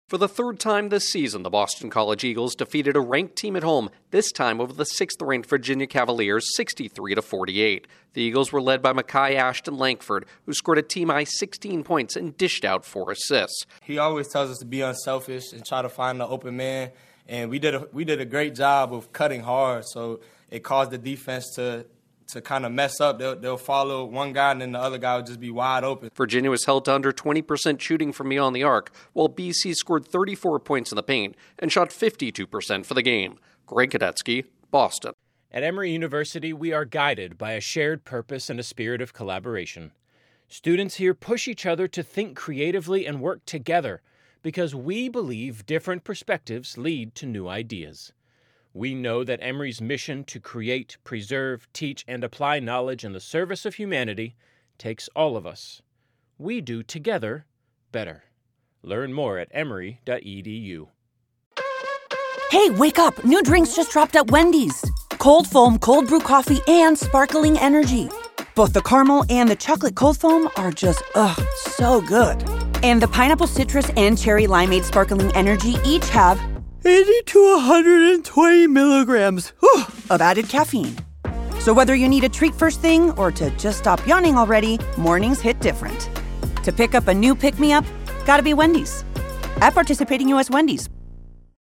Boston College plays the role of giant-killers again. Correspondent